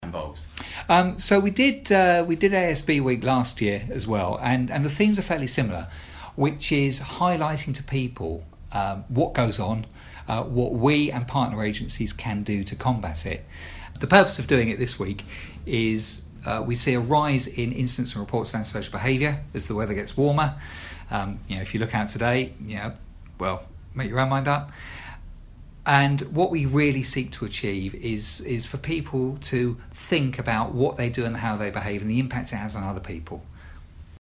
Anti-social behaviour week launch - Eagle Radio interview clip